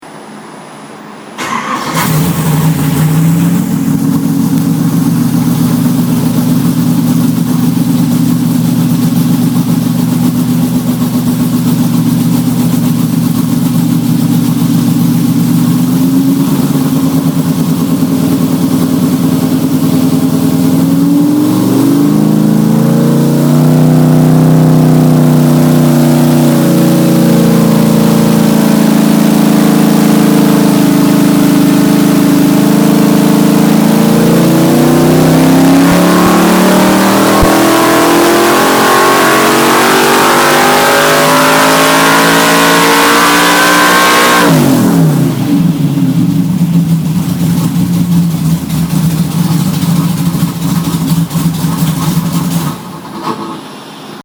Here are links to a couple of sound clips of a iron headed drag race Y engine making in excess of 500 HP through the mufflers.
500+ Horsepower dyno pull 1
This is an iron headed racing Y with more compression ratio than allowed for the EMC competition and more camshaft than I have in the EMC entry.